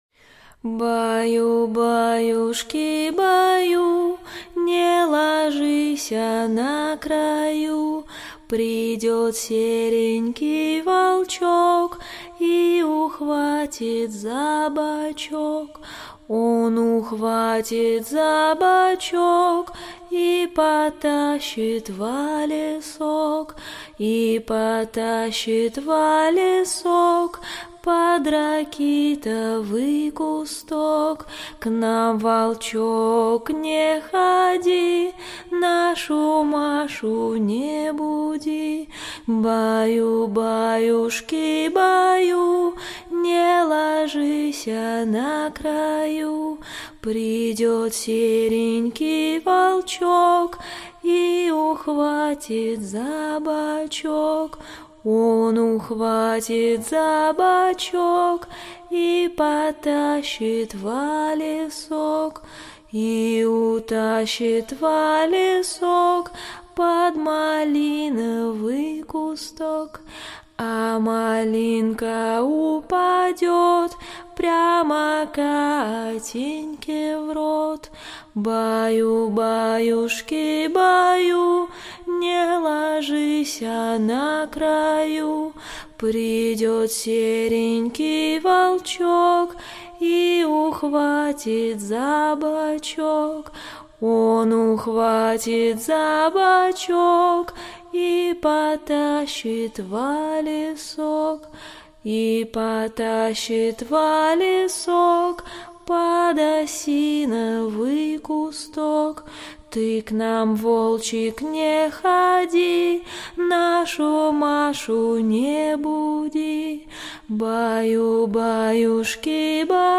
Колыбельные